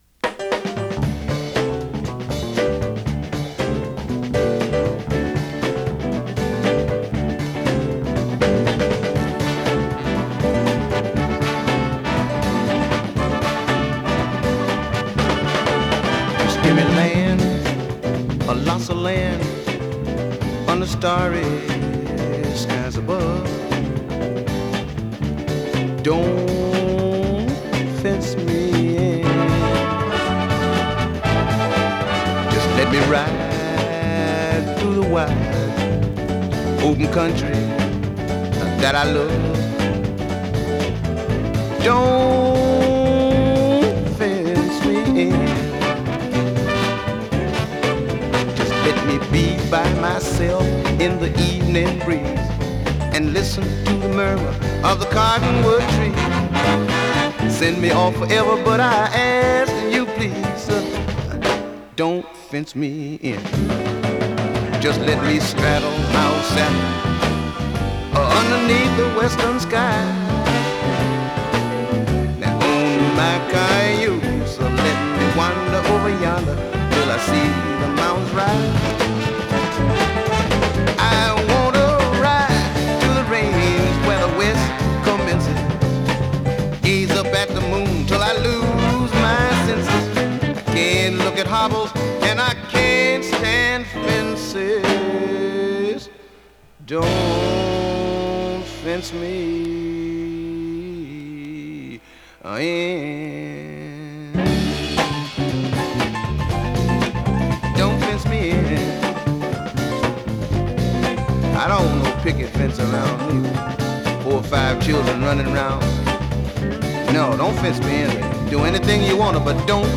＊音の薄い部分で時おり軽いチリ/パチ・ノイズ。